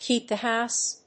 アクセントkéep (to) the hóuse